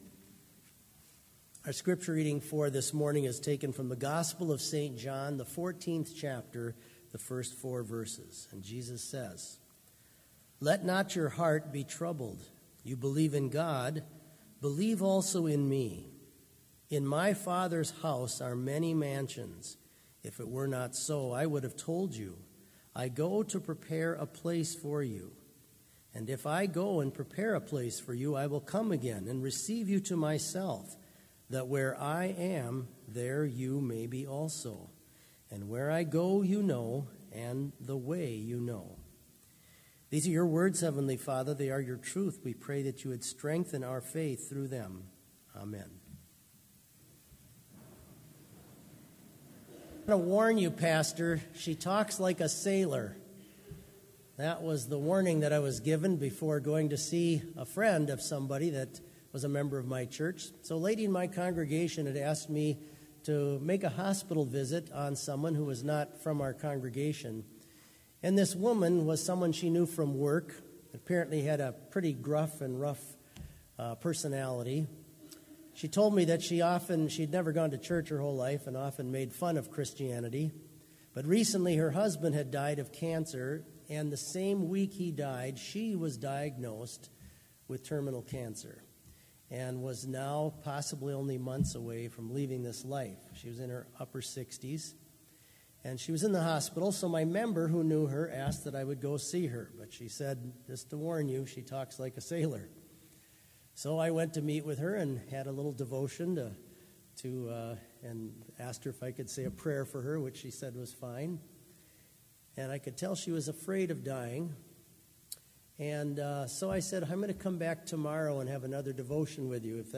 Complete service audio for Chapel - February 4, 2019